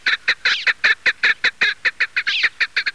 На этой странице собраны звуки, издаваемые куропатками различных видов.
Азиатский горный кеклик